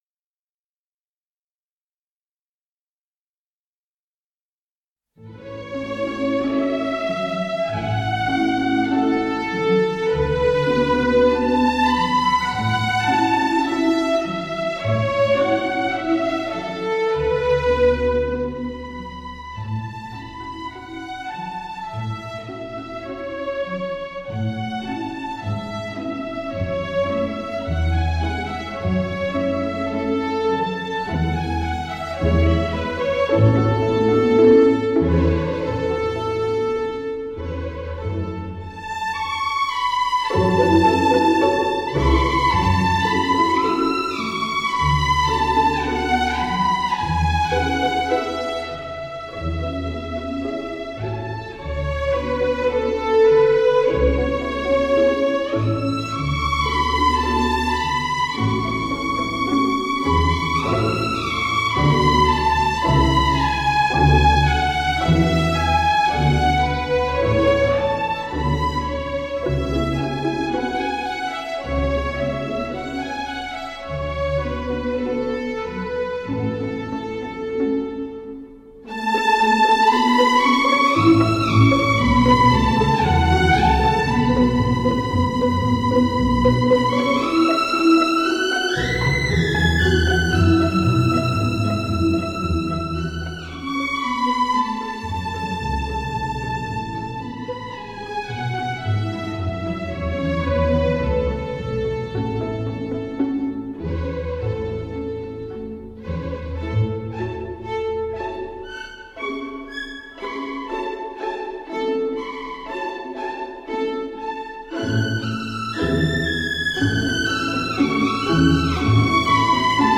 旋律流畅明亮。激情有力。全曲短小精悍，一气呵成。